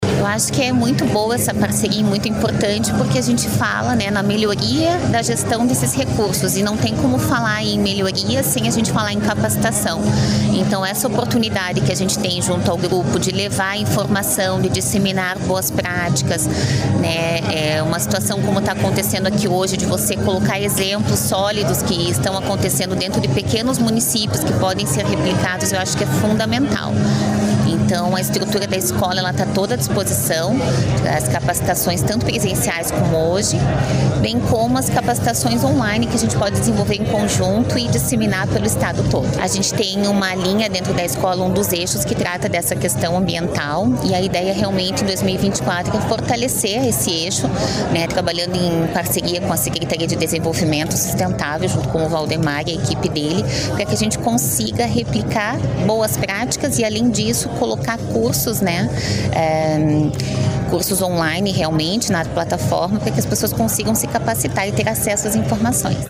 Sonora da diretora da Escola de Gestão do Paraná, Aline Justus, sobre a parceria com a Sedest para capacitar servidores para logística reversa e plano de resíduos